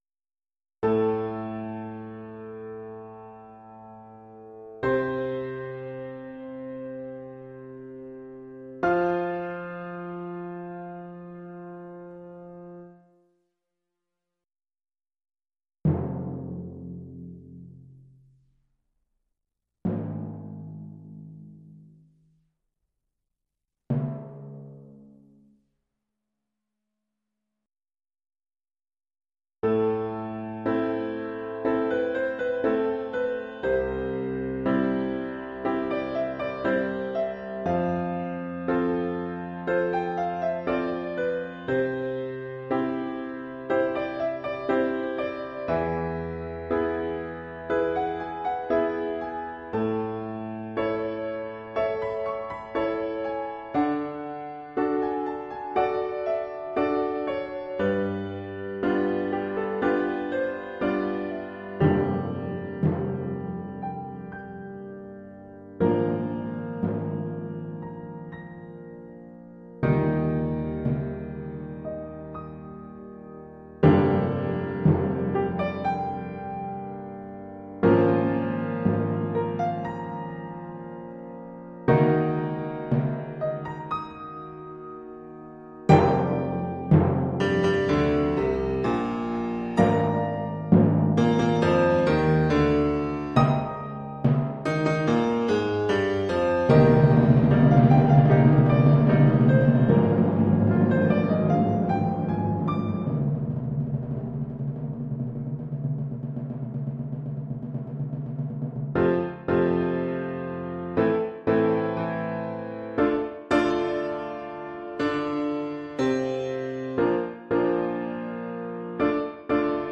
Oeuvre pour 3 timbales et piano.